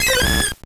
Cri de Corayon dans Pokémon Or et Argent.